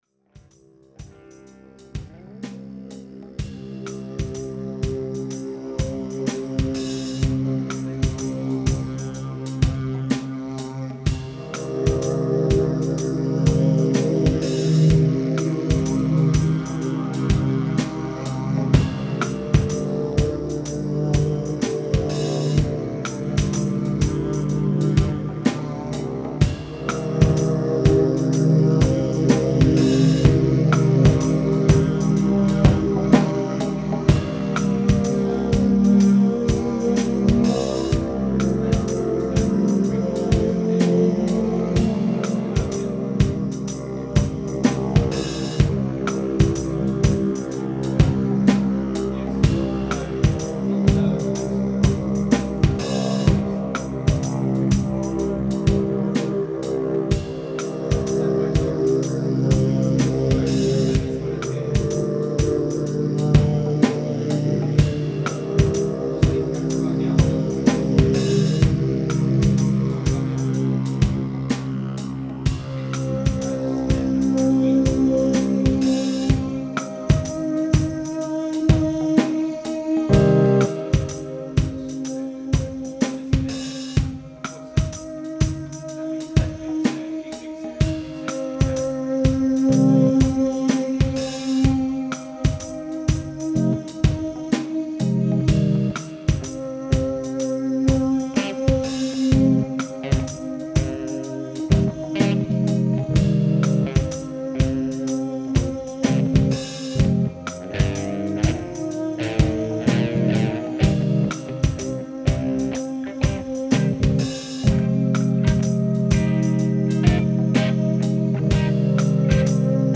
(jam)
didgeridoo